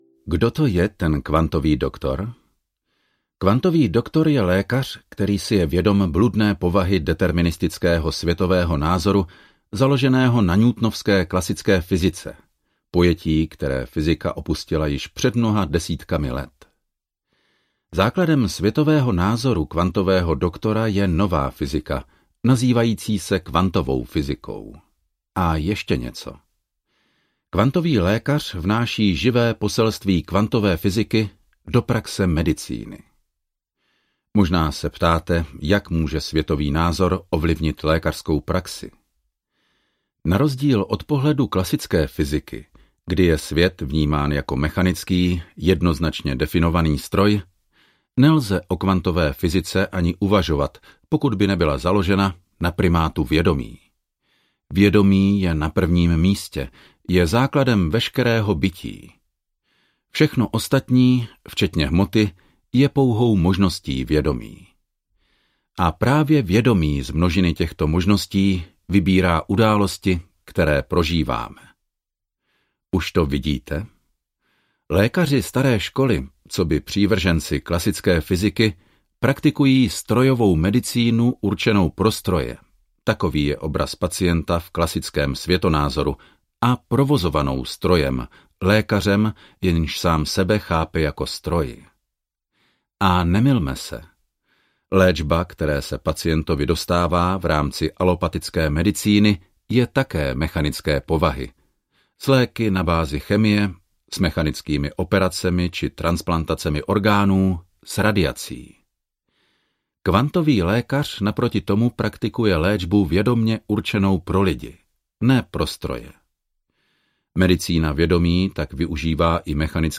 Kvantový doktor audiokniha
Ukázka z knihy
kvantovy-doktor-audiokniha